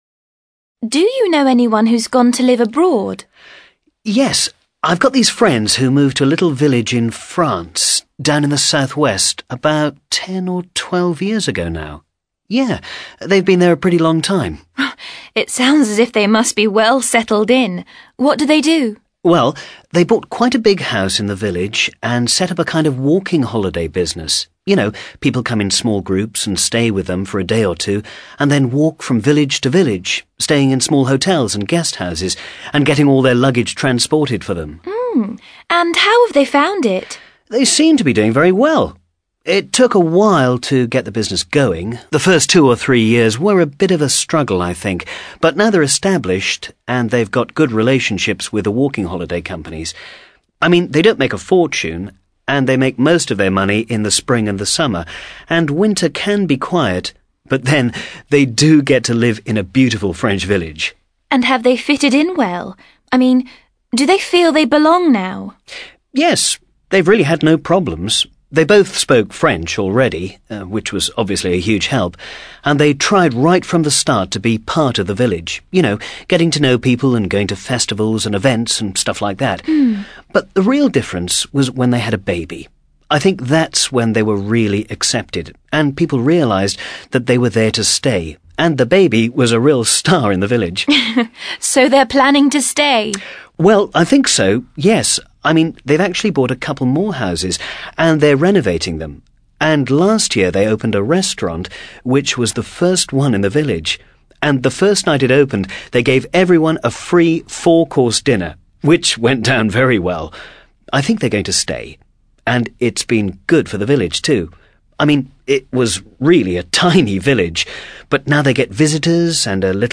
ACTIVITY 93: You will listen to a man talking to a woman about some friends who went to live in France.